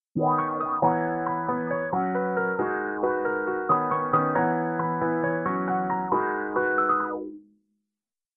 それとフィルターを組み合わせると
みょいんみょいんってやつっ！